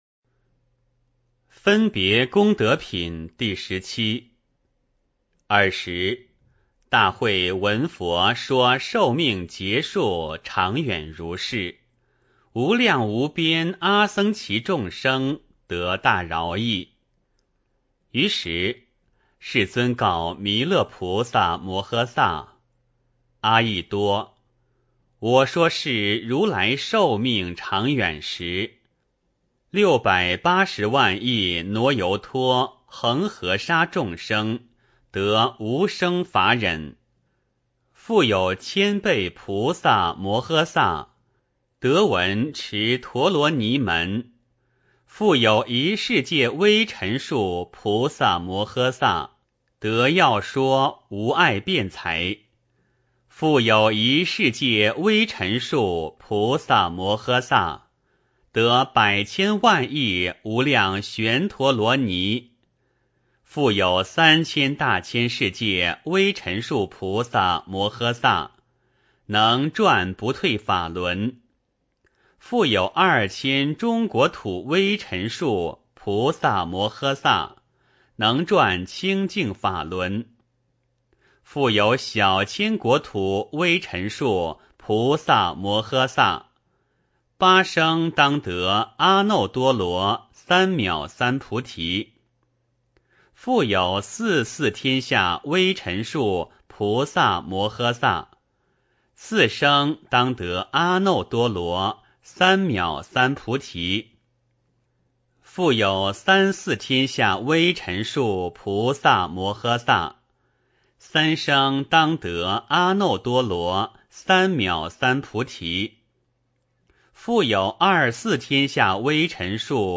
法华经-分别功德品第十七 - 诵经 - 云佛论坛